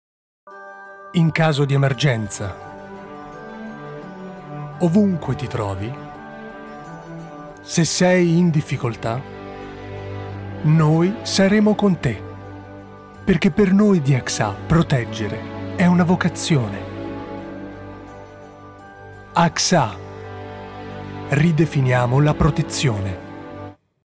Speaker radio-televisivo. Voce calda e graffiata
Sprechprobe: Industrie (Muttersprache):